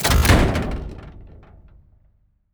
reload2.wav